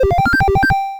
retro_beeps_collect_item_03.wav